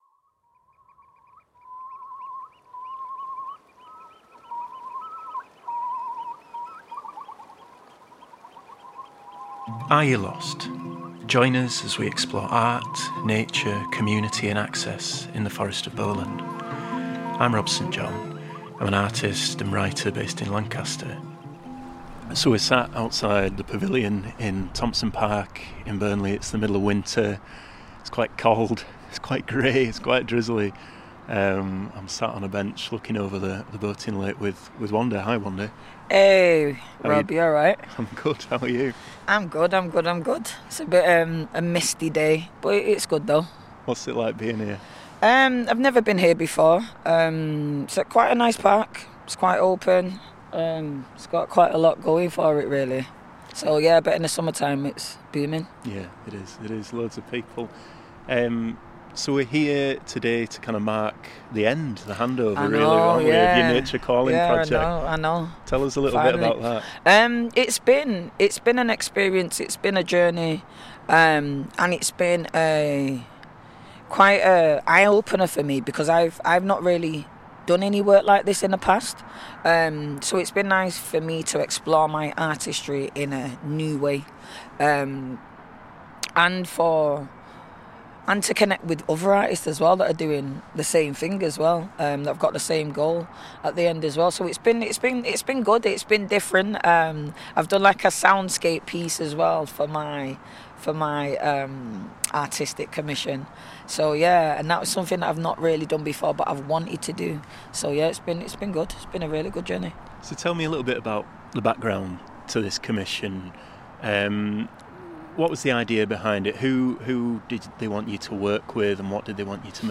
Speaking in Thompson Park, Burnley
We hear from a series of young people from Burnley and Nelson telling us about…